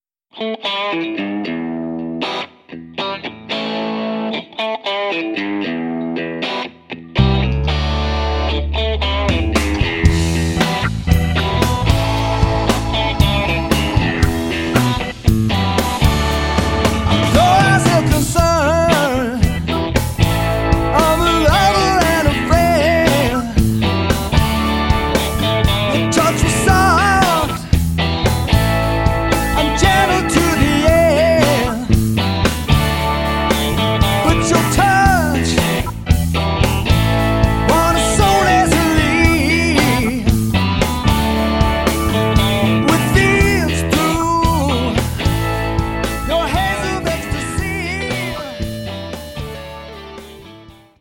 old school rock, funk and R&B
vocals
guitar
keyboards